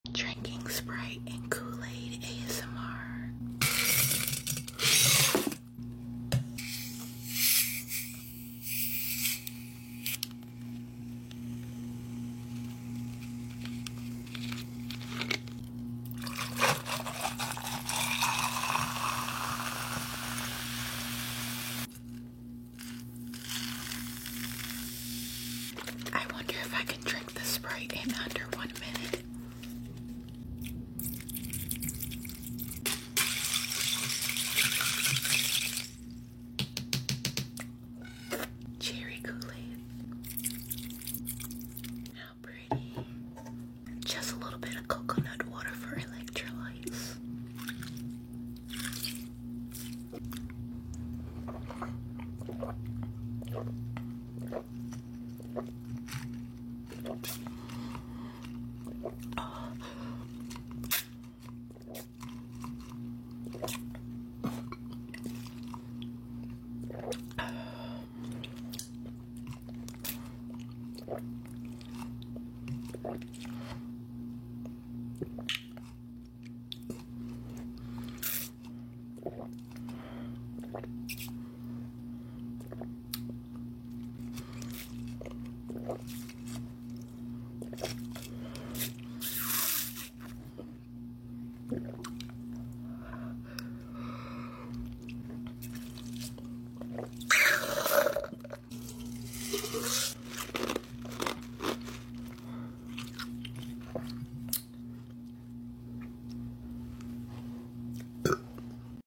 Sprite & Kool Aid ASMR Sound Effects Free Download